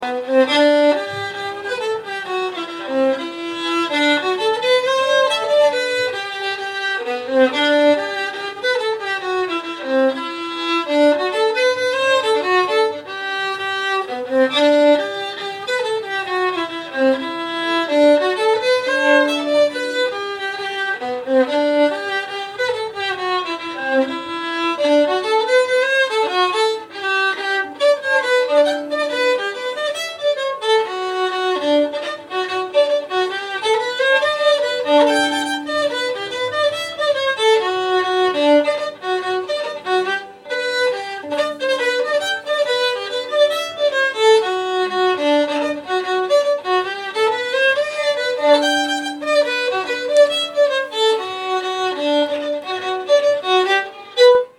Schottis och Reinländer